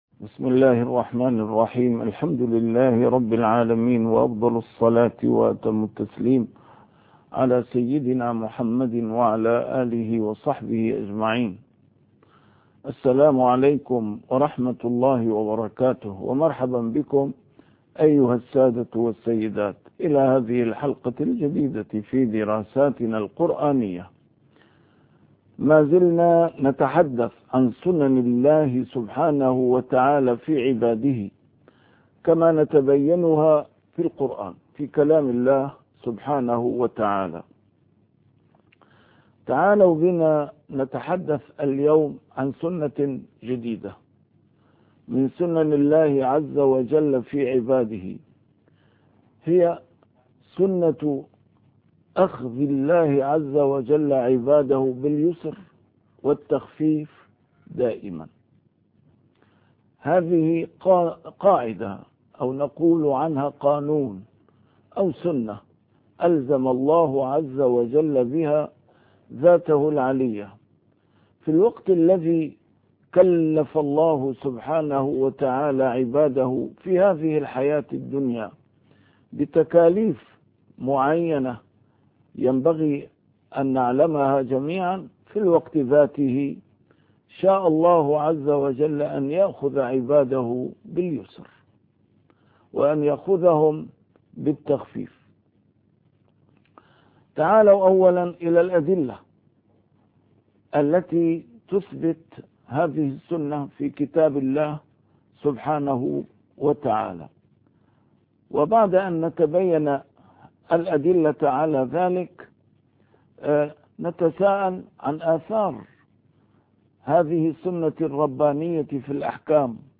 A MARTYR SCHOLAR: IMAM MUHAMMAD SAEED RAMADAN AL-BOUTI - الدروس العلمية - درسات قرآنية الجزء الثاني - 13- سنن الله في عباده